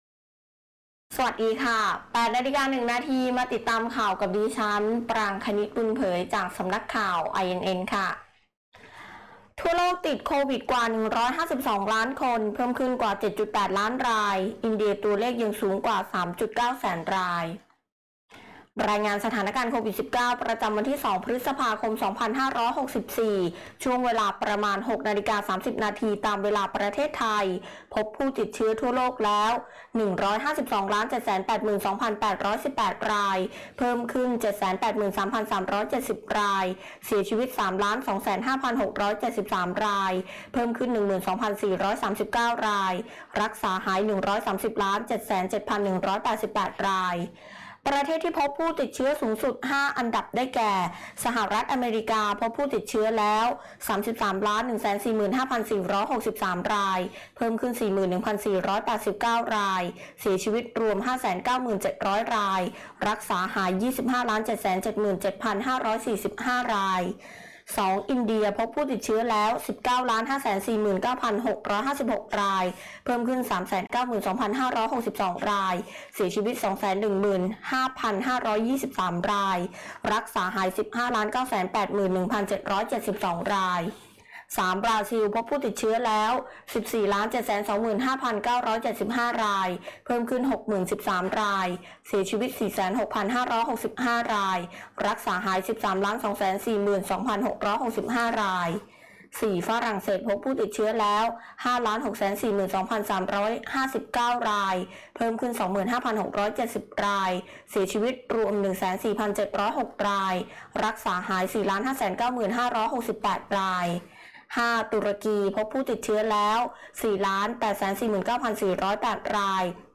ข่าวต้นชั่วโมง 08.00 น.